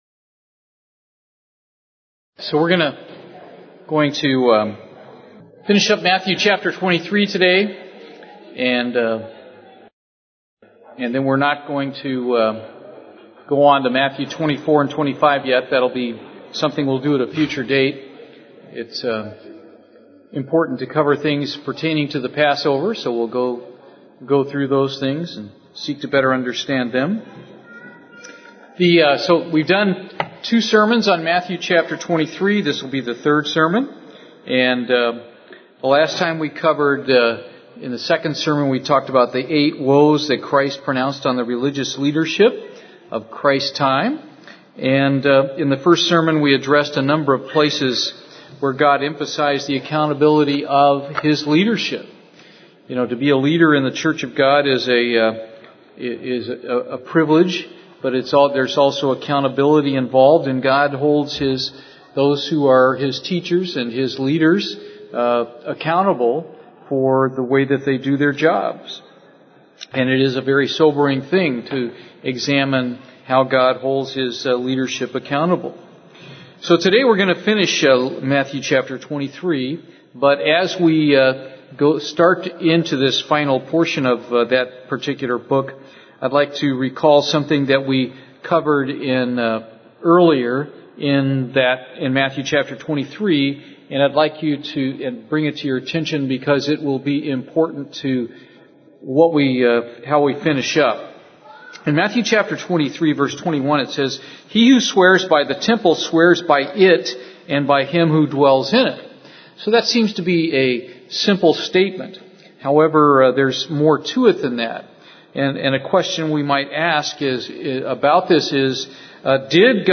This Bible study completes the section of the fifth discourse of Jesus covered in Matthew chapter 23 dealing with Jesus's judgment of the leaders of the Jewish religion.